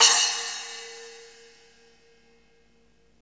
Index of /90_sSampleCDs/Roland L-CD701/CYM_Cymbals 1/CYM_Cymbal menu
CYM SPLAS0BL.wav